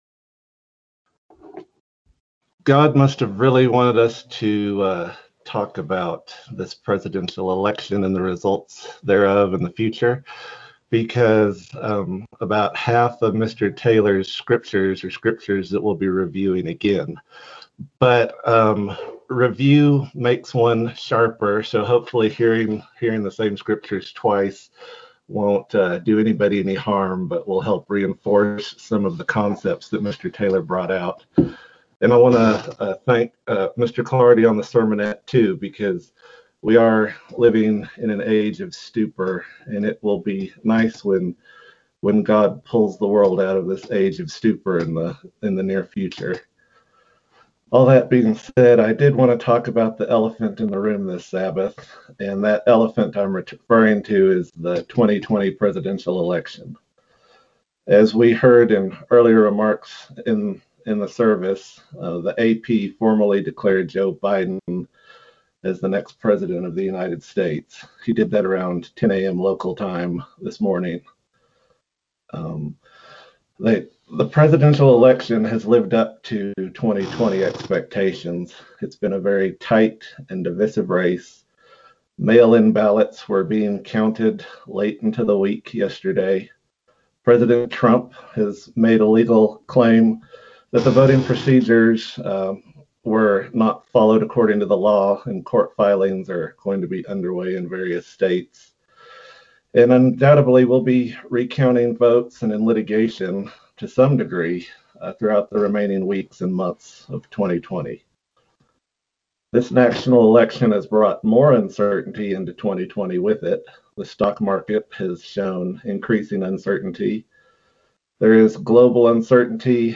This sermon examines 4 ways in which God is preeminent above this national election. The message then discusses calls to action that every Christian has, based on the presented information.
Given in Lubbock, TX